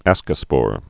(ăskə-spôr)